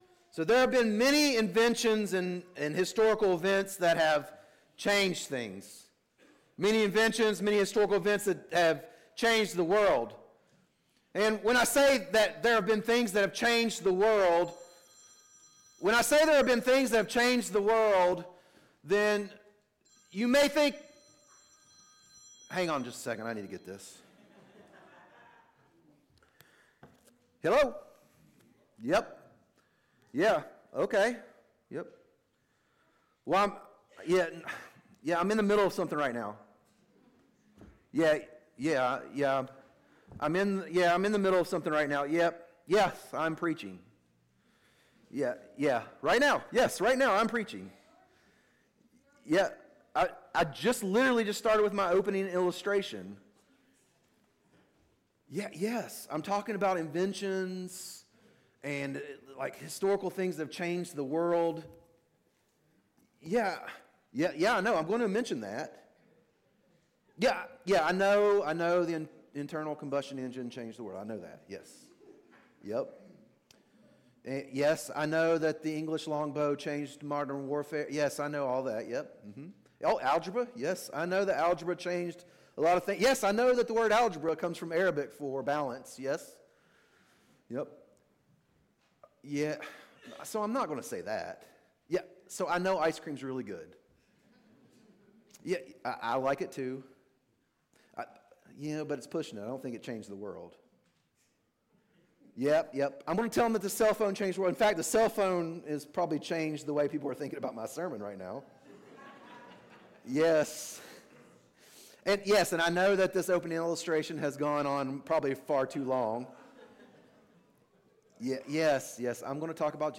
Sermons | Scranton Road Bible Church